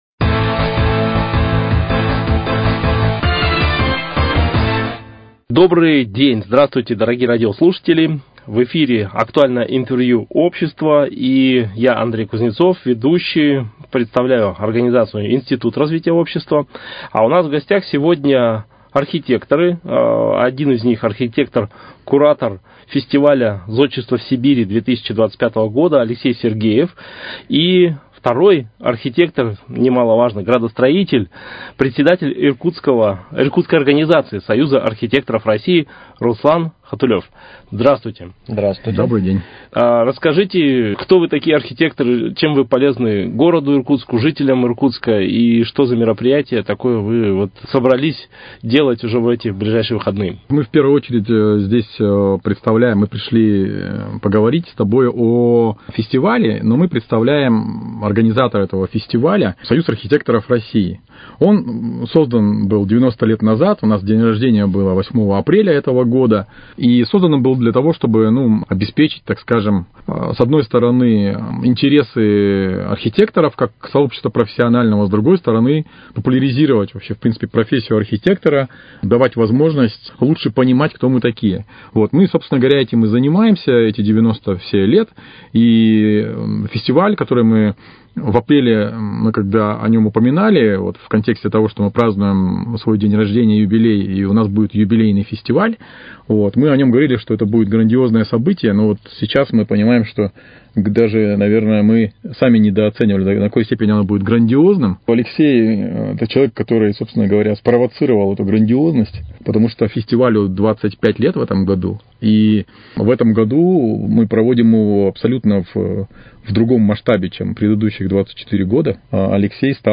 Актуальное интервью: О фестивале «Зодчество в Сибири»